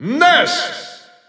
Announcer pronouncing Ness's name in German.
Ness_German_Announcer_SSBU.wav